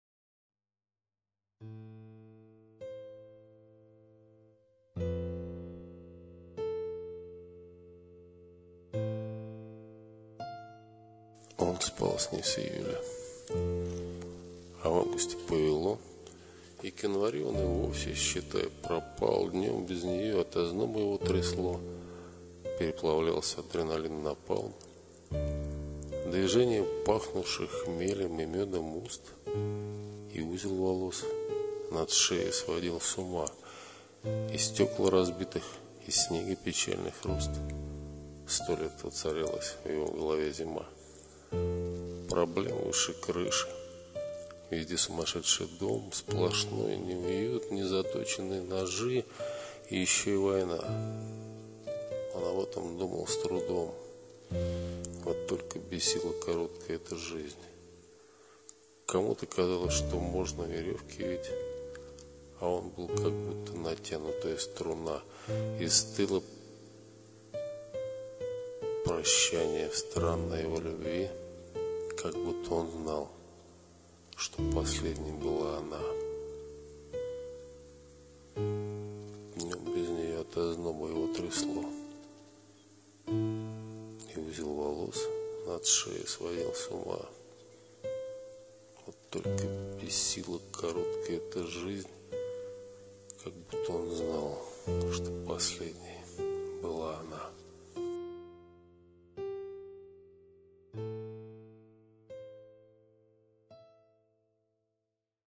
Мелодекламация